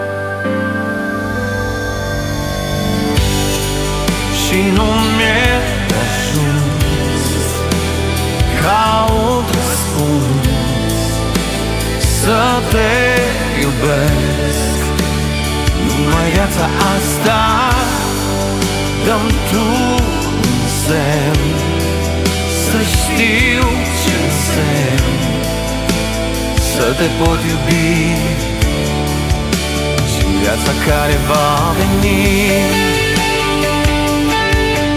Жанр: Поп музыка / Рок
Pop, Rock